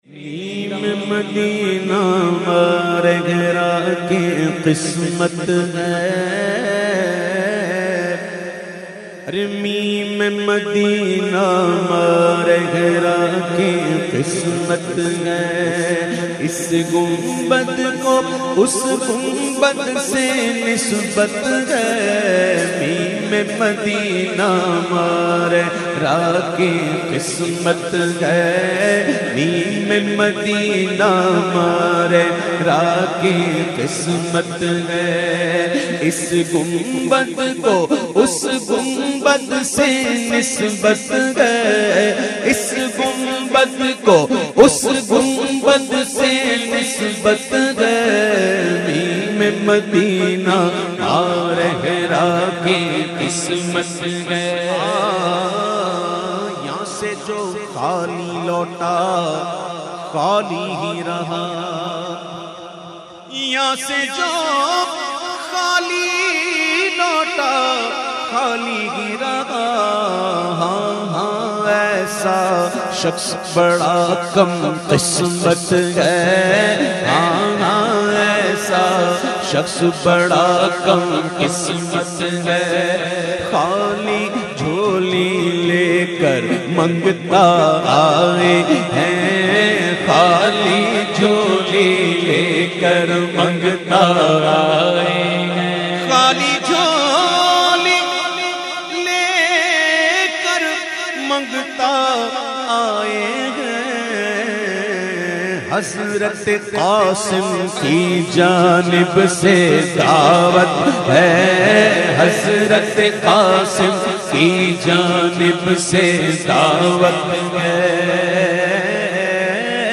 The Naat Sharif Meem e Madina recited by famous Naat Khawan of Pakistan Owais Raza Qadri.